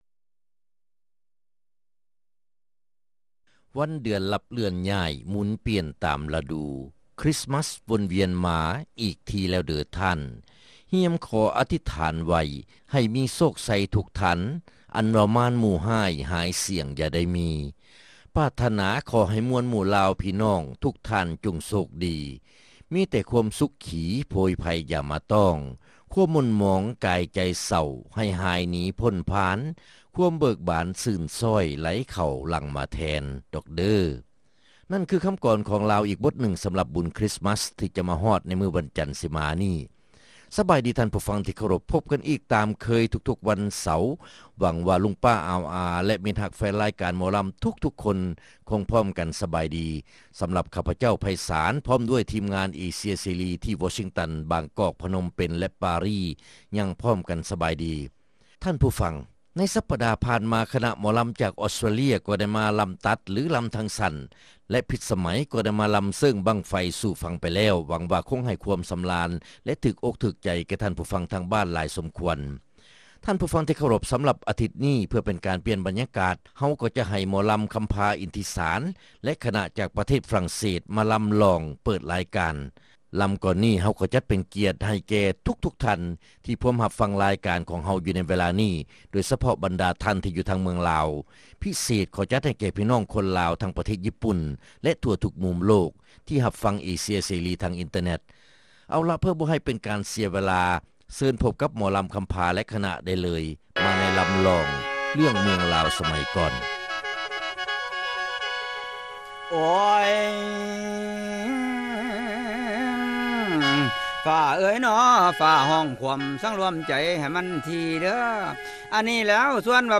ຣາຍການໜໍລຳ ປະຈຳສັປະດາ ວັນທີ 22 ເດືອນ ທັນວາ ປີ 2006